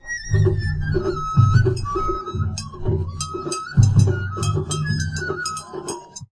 ninja_greeting.ogg